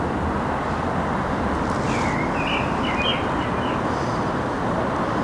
Observação BirdNET - Melro-preto - 2022-04-23 20:43:31
Melro-preto observado com o BirdNET app. 2022-04-23 20:43:31 em Lisboa